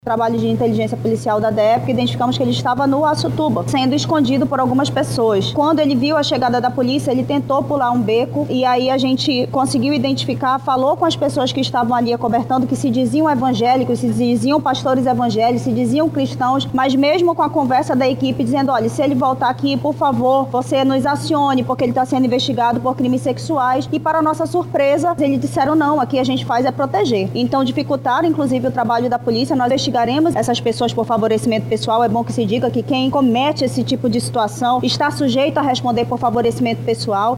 Ainda segundo a delegada, o homem estava escondido com a ajuda de integrantes da comunidade religiosa a qual faz parte.